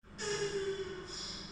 turtle_moan.ogg